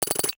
NOTIFICATION_Metal_06_mono.wav